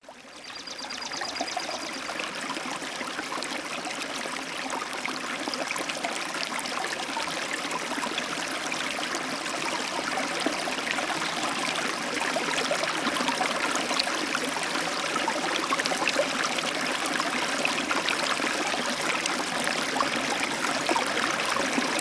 На этой странице вы можете слушать онлайн или бесплатно скачать успокаивающие записи журчания свежей ключевой воды.
Звук бьющего из под земли родника